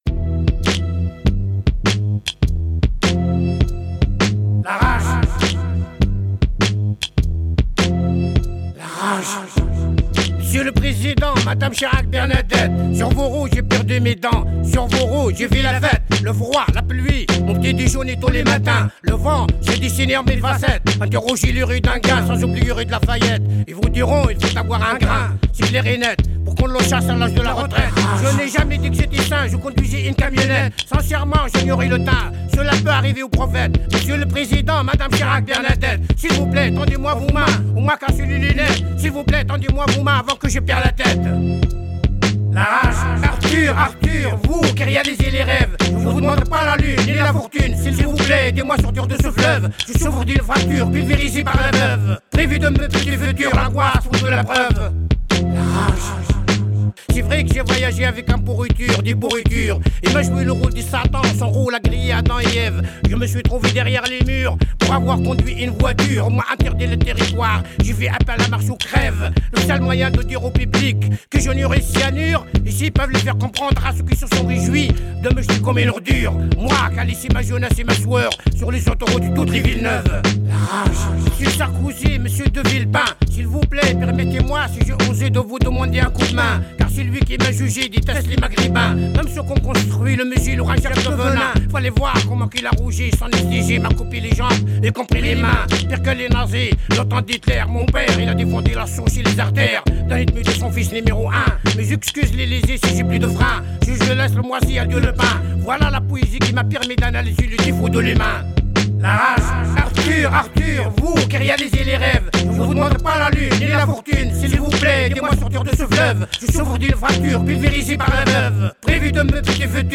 Stop Arming Israel France - Radio podcast - Groupe Louise-Michel